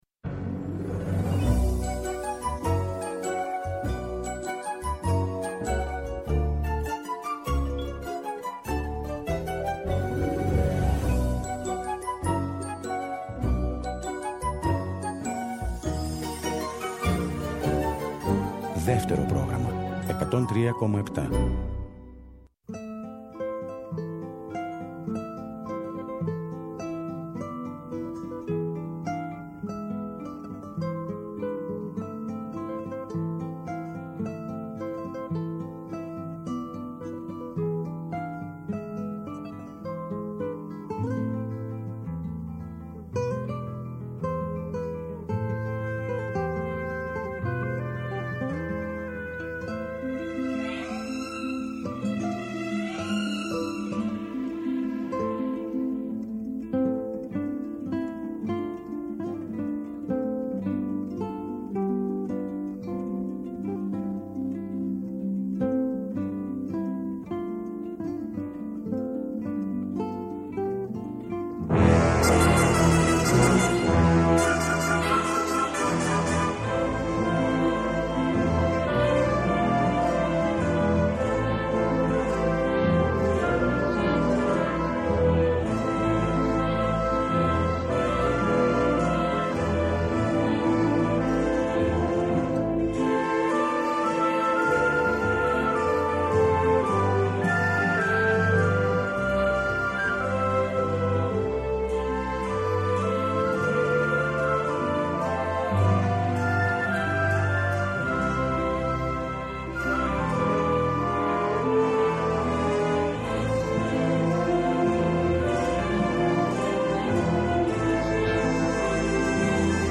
Οι Πλανόδιες μουσικές επιχειρούν ένα ταξίδι σε τραγούδια που έχουμε αγαπήσει και σε τραγούδια που ίσως αγαπήσουμε.